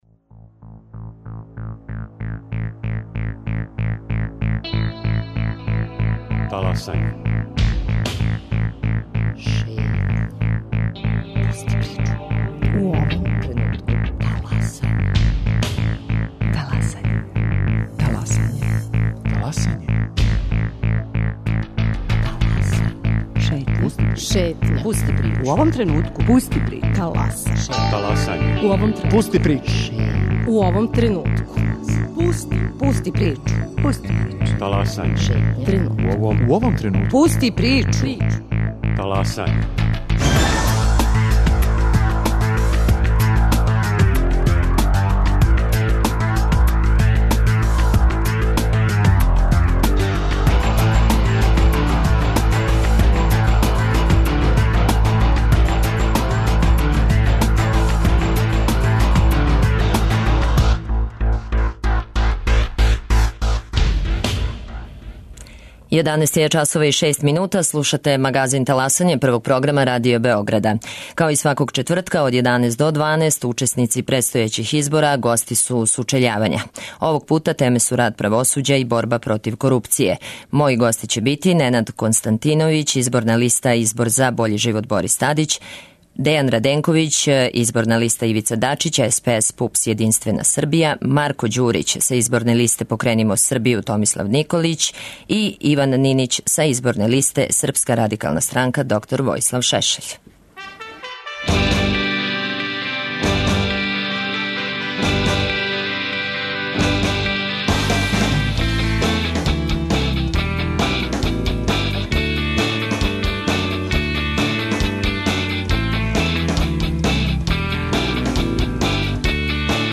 Као и сваког четвртка до 6. маја, у термину од 11 до 12 часова, учесници предстојећих избора учествују у сучељавању. Овог пута теме су реформа правосуђа и борба против корупције.